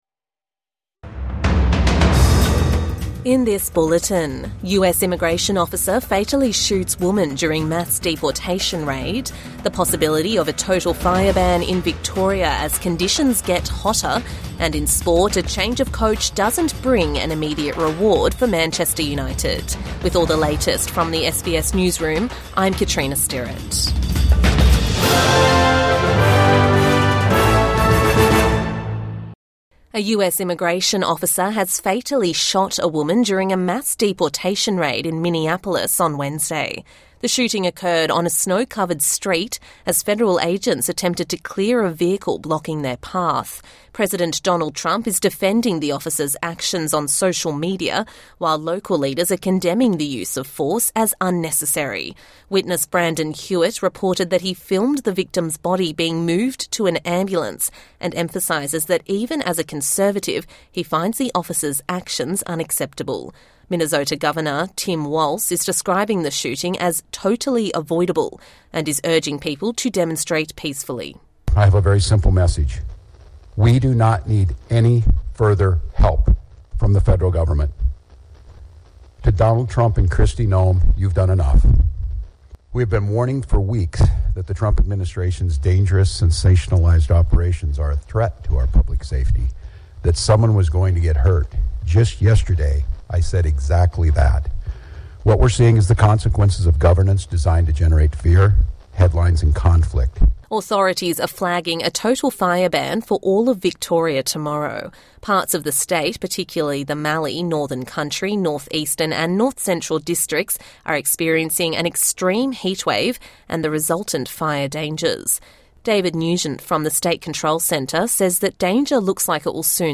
A bulletin of the day’s top stories from SBS News. Get a quick rundown of the latest headlines from Australia and the world, with fresh updates each morning, lunchtime and evening.